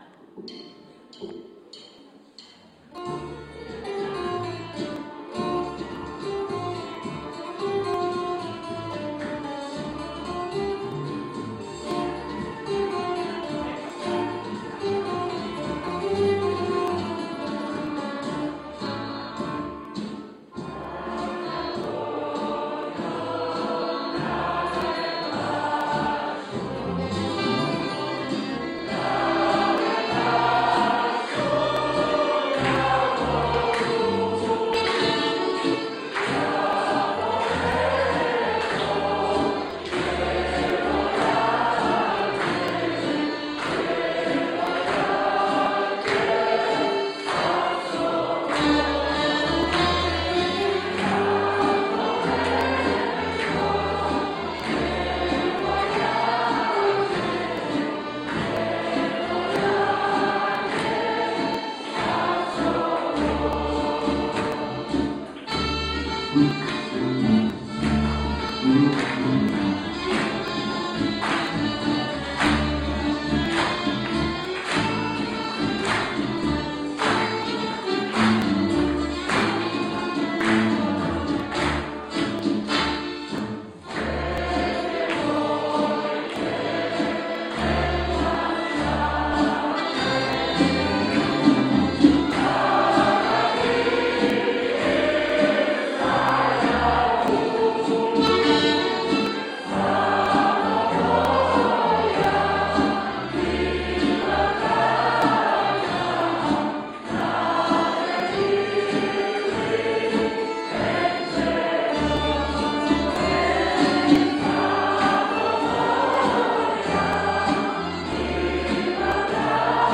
Holanđani pjevaju sevdalinke
Hor „Slavuj“ iz Amsterdama već 26 godina na repertoaru ima izvornu muziku sa Balkana, odnosno pjesme iz zemalja slavenskog govornog područja, ili, kako vole reći, iz zemalja oko Crnog mora - od Rumunije, Bugarske, Ukrajine, Rusije, Turske, do država nastalih na prostoru bivše Jugoslavije.
Bentbaša u izvedbi hora 'Slavuj'